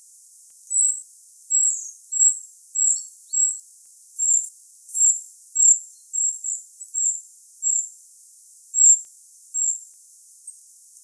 Regulus regulus - Goldcrest - Regolo
- IDENTIFICATION AND BEHAVIOUR: Two birds - in sight - are feeding among the branches of a high fir in a coniferous forest.
- COMMENT: Note the lower pitch of this "srrii" call type compared to the previous recording. - MIC: (built in directional microphone of Tascam DR100-MKIII)